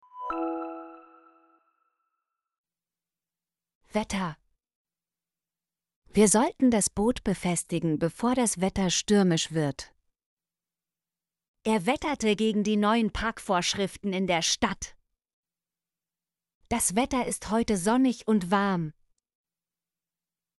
wetter - Example Sentences & Pronunciation, German Frequency List